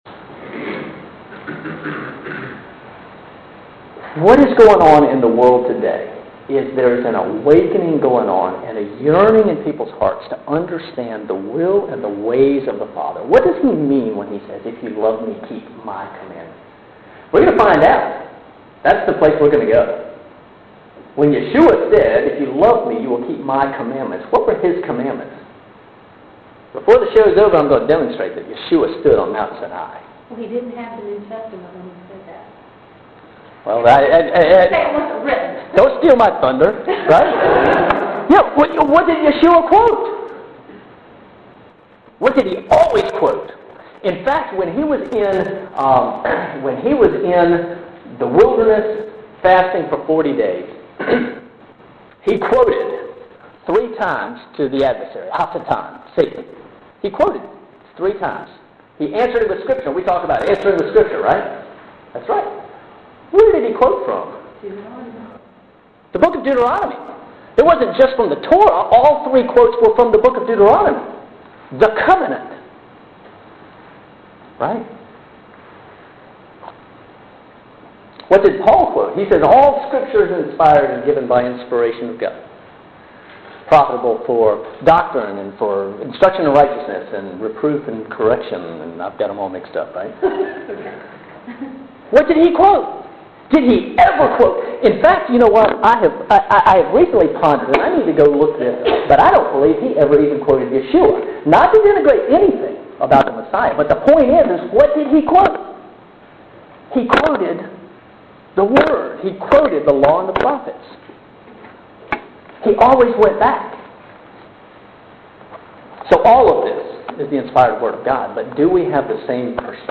So…, I’m teaching a Sunday School class!
Frankly, the audio is not great, mostly because it was an after thought. We were already 15 minutes underway as I introduced myself and gave some background when the gentleman decided to place a small pocket recorder on the podium. Following is a recording of the last 29 minutes or so of class.